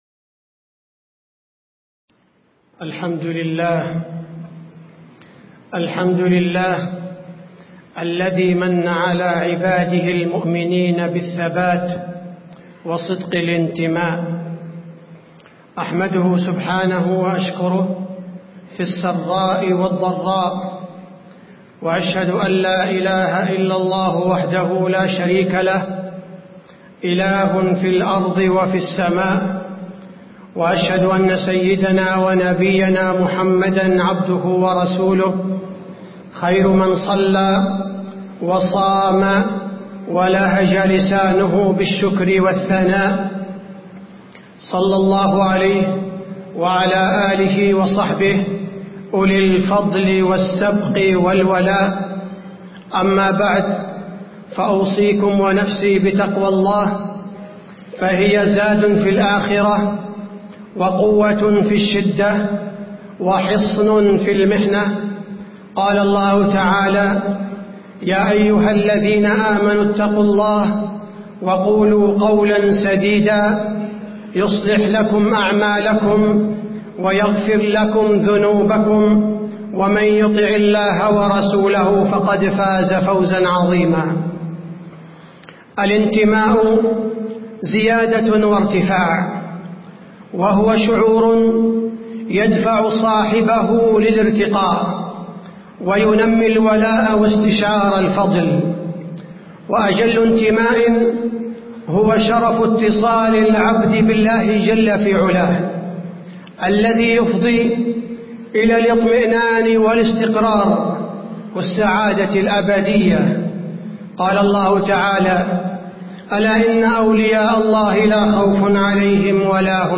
تاريخ النشر ٢٢ صفر ١٤٣٧ هـ المكان: المسجد النبوي الشيخ: فضيلة الشيخ عبدالباري الثبيتي فضيلة الشيخ عبدالباري الثبيتي الإنتماءإلى الإسلام